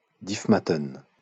Diefmatten (French pronunciation: [difmatən]